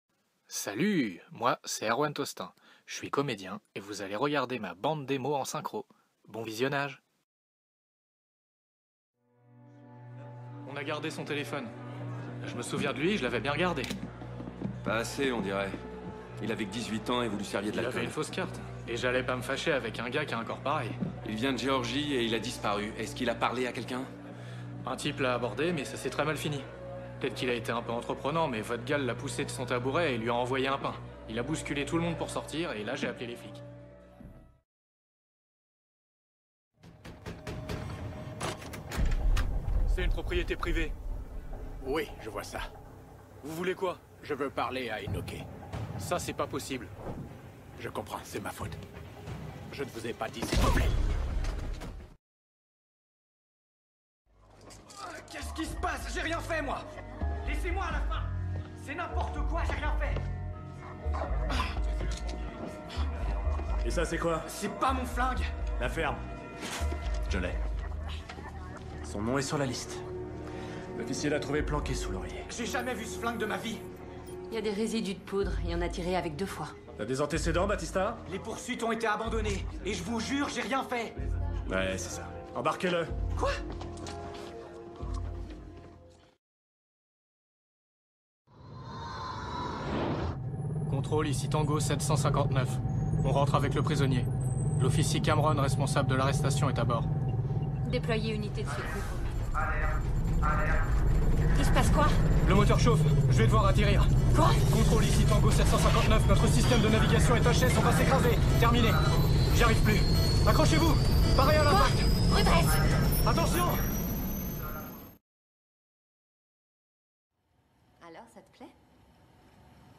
Voix off
voix